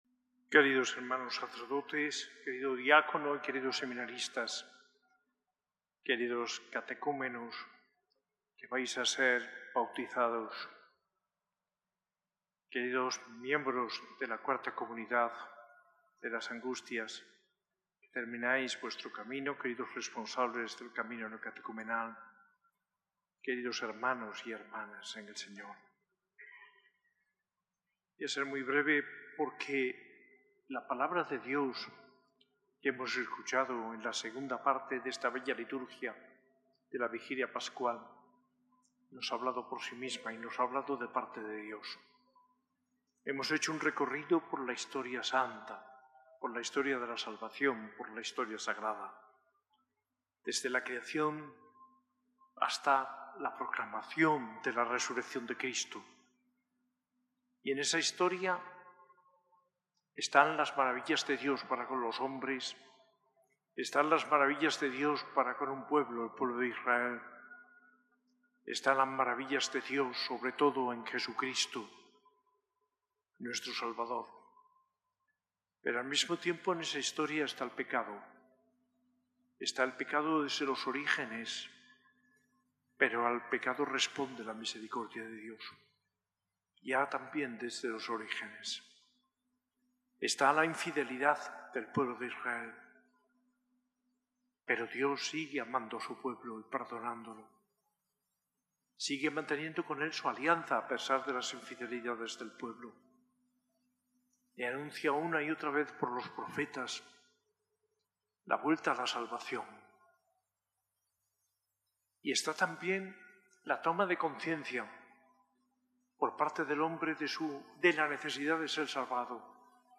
Homilía del arzobispo de Granada, Mons. José María Gil Tamayo, en la celebración de la Vigilia Pascual, el 19 de abril de 2025, en la S.A.I Catedral.